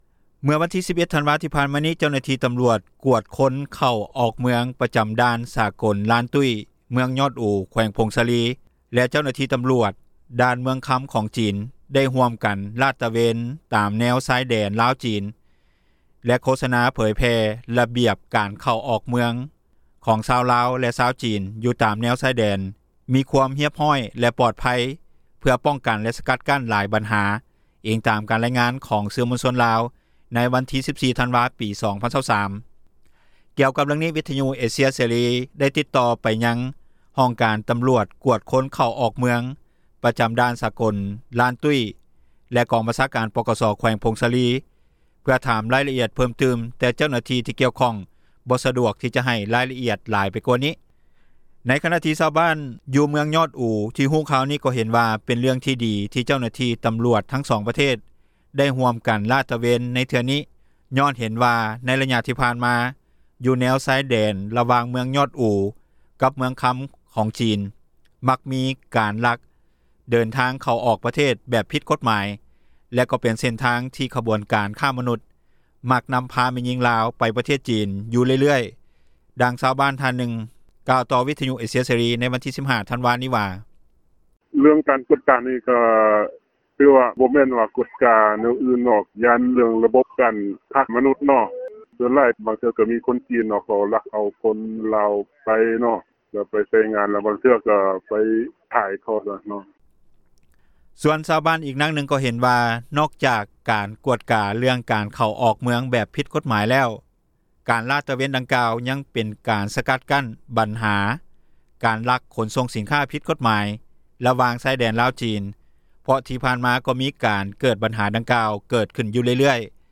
ດັ່ງຊາວບ້ານທ່ານນຶ່ງ ກ່າວຕໍ່ວິທຍຸເອເຊັຽເສຣີ ໃນວັນທີ 15 ທັນວານີ້ວ່າ:
ດັ່ງຊາວບ້ານ ອີກທ່ານນຶ່ງກ່າວວ່າ: